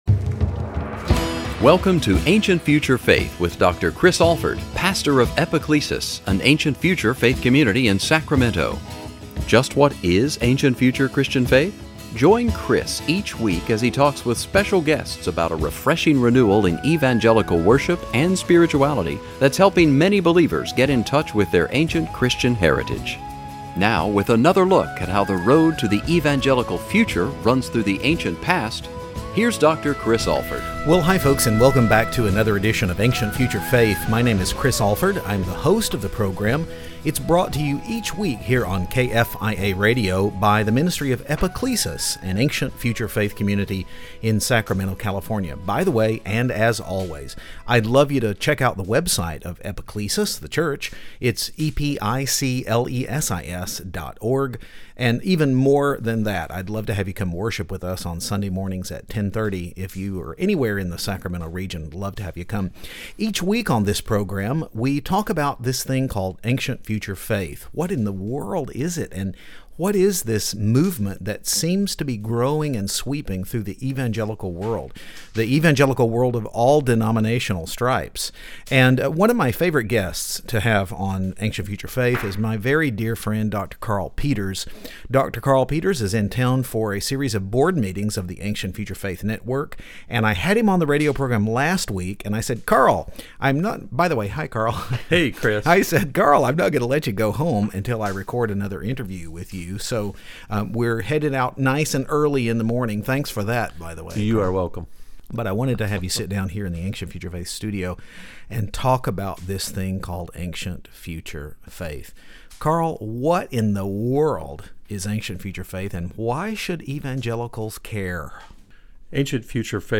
was back in the studio for a conversation